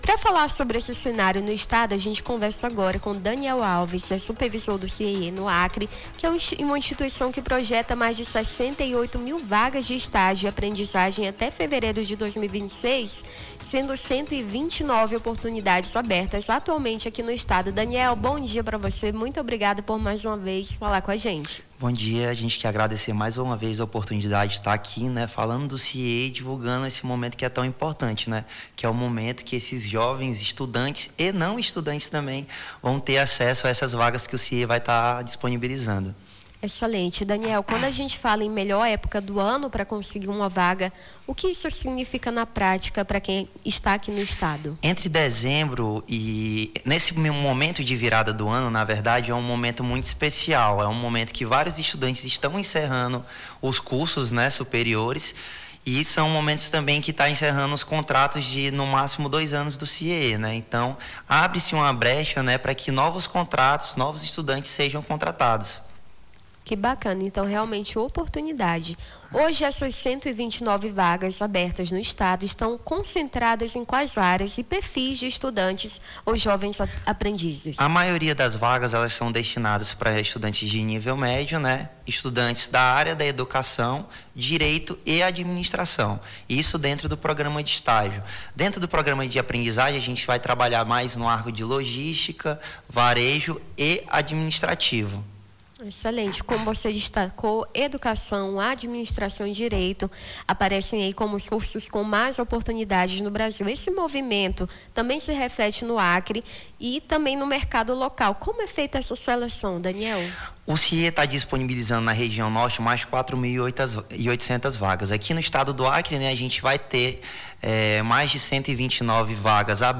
Nome do Artista - CENSURA - ENTREVISTA (VAGAS ESTAGIO CIEE) 17-12-25.mp3